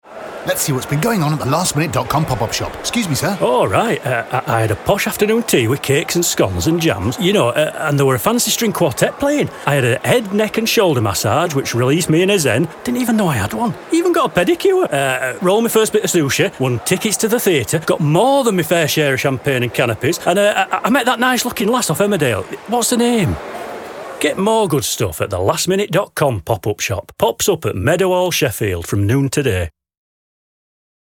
Voice Over Projects